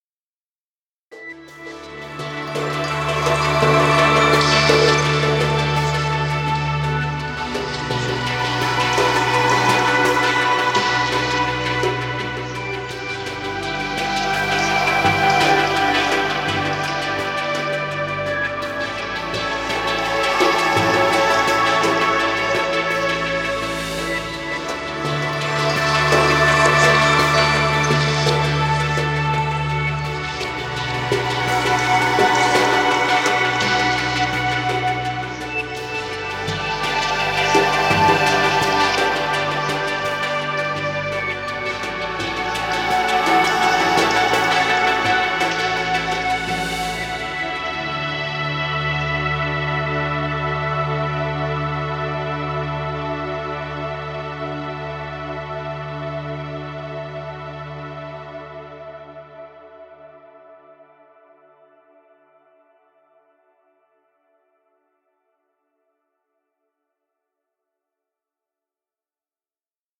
Ambient music.